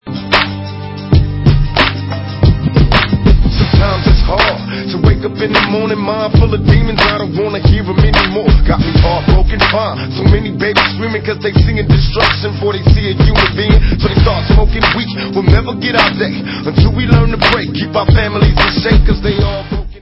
Dance/Hip Hop